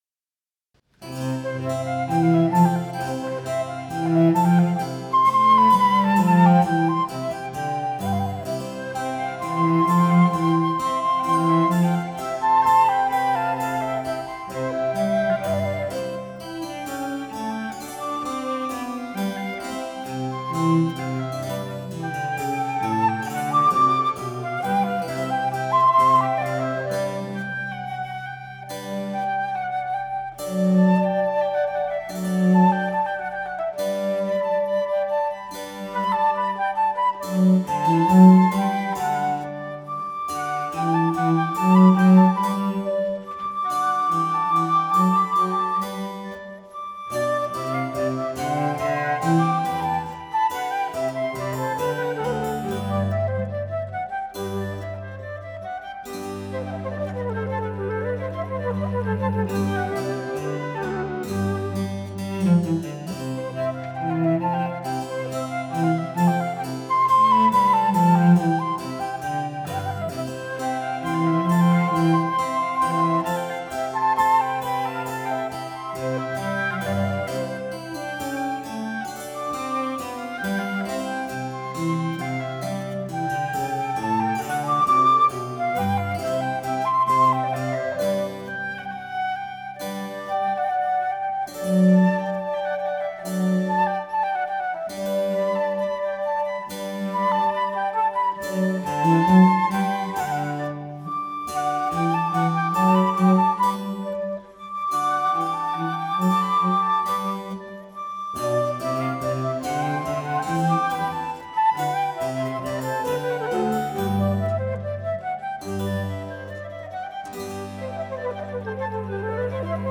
Incamto ensemble | Live recording | InCamTo
flauti
clavicembalo
violoncello
Chiesa di Santa Chiara, Torino